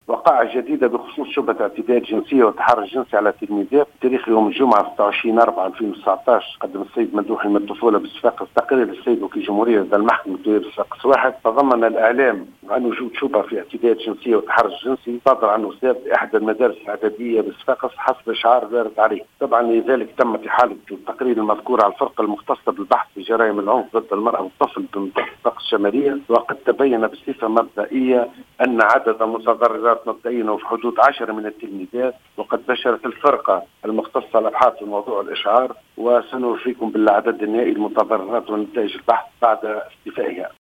أكد الناطق الرسمي باسم محاكم صفاقس القاضي مراد التركي في تصريح للجوهرة اف ام...